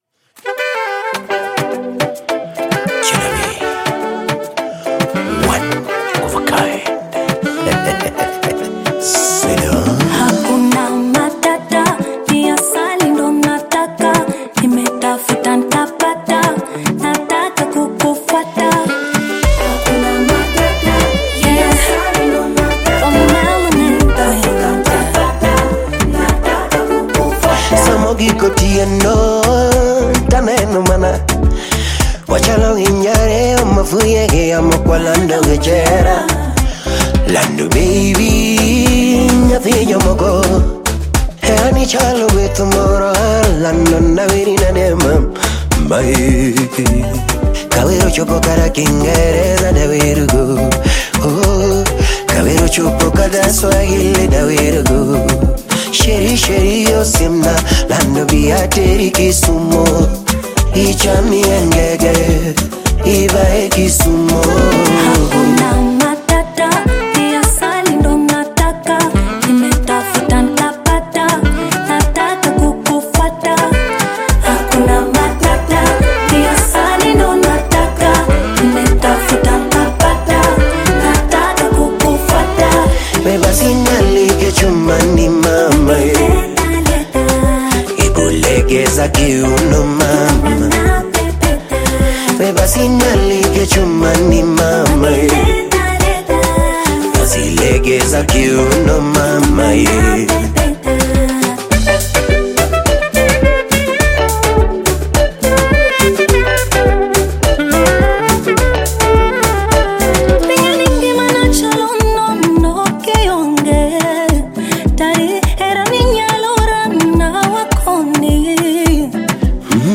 Kenyan song